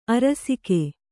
♪ arasike